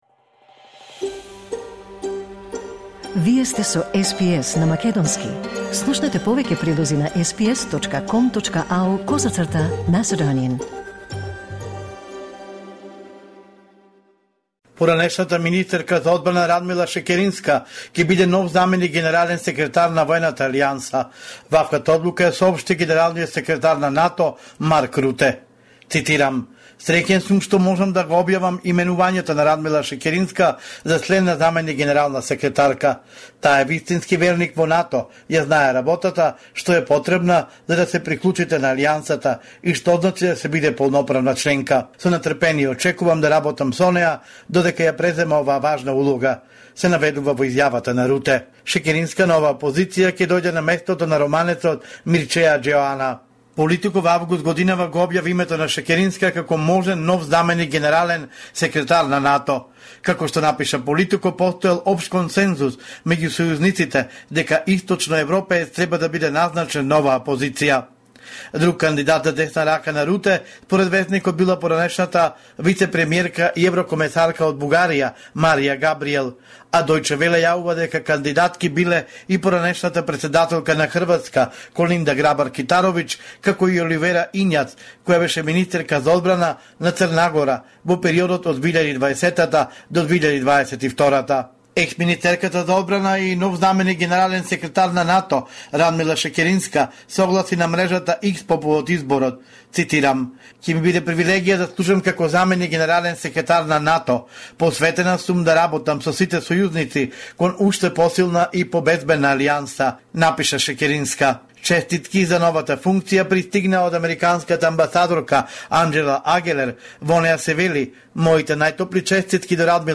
Homeland Report in Macedonian 20 November 2024